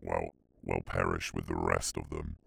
Voice Lines
well well perish with the rest of them.wav